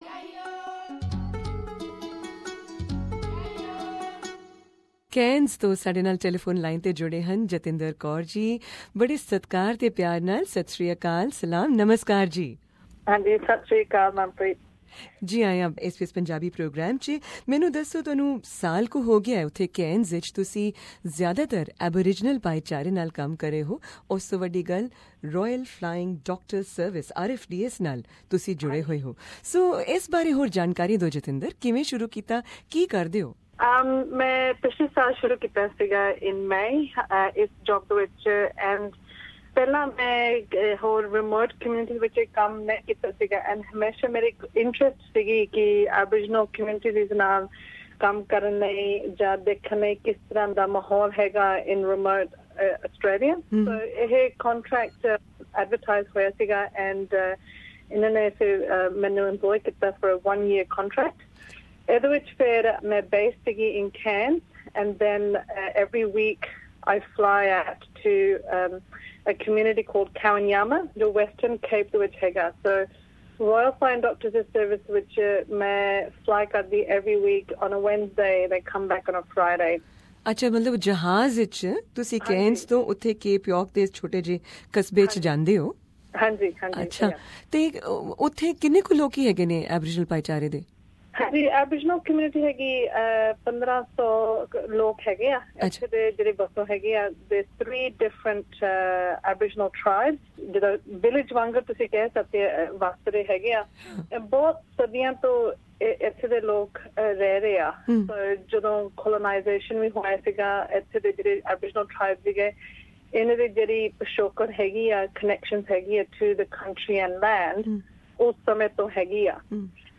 Here is the podcast of this very special interview.